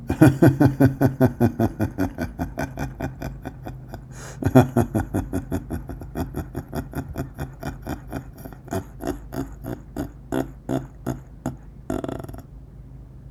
025_LAUGH NORMALIZE.wav